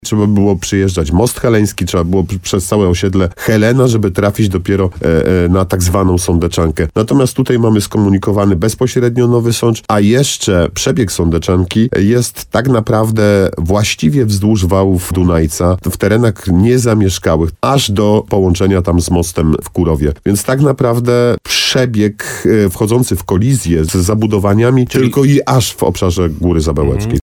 – Po pierwsze byłyby to o wiele większe koszty, a po drugie, ten przebieg też nie byłby korzystny – mówił Artur Bochenek w programie Słowo za Słowo w radiu RDN Nowy Sącz.